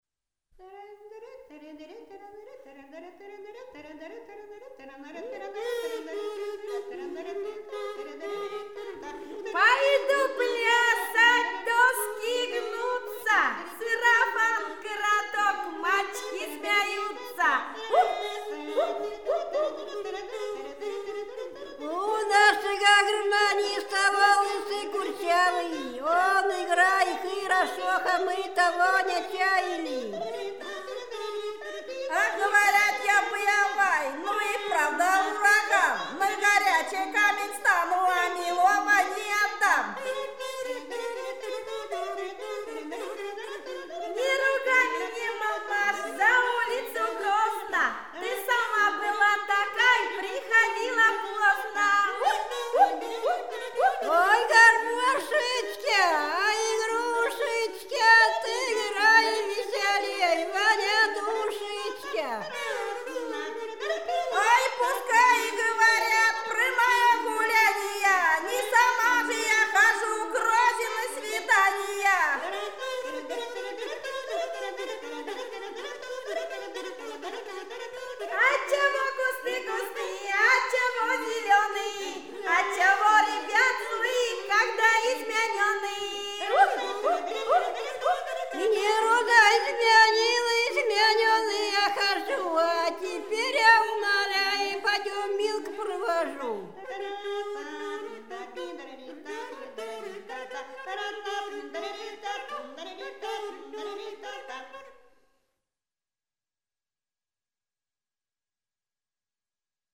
Рязань Секирино «Пойду плясать», частушки под «дилиньканье» и гребень.